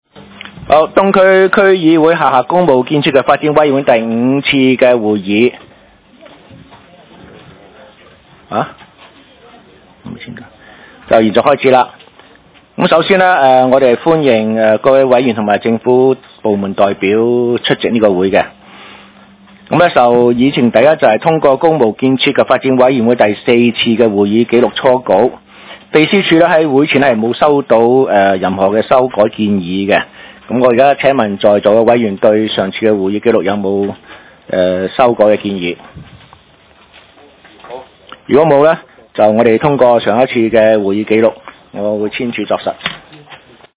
東區法院大樓11樓東區區議會會議室